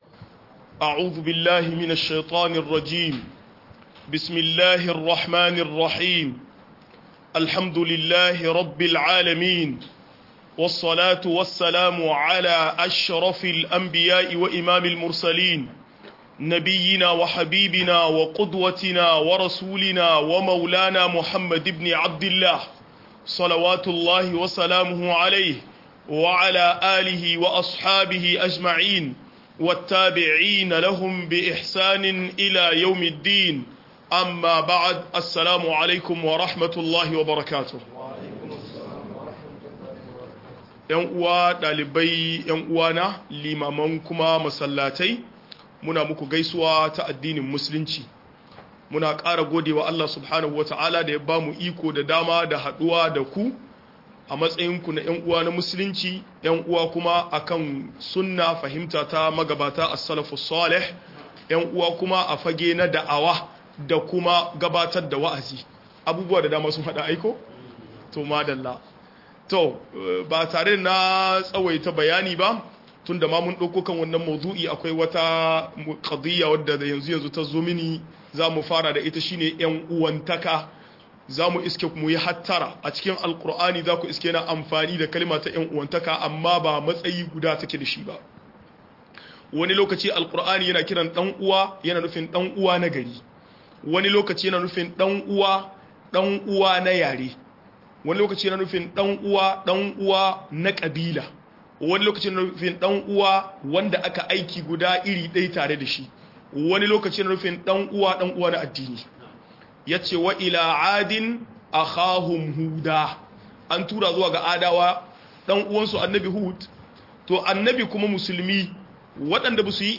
Abin da ke hana neman ilimi - MUHADARA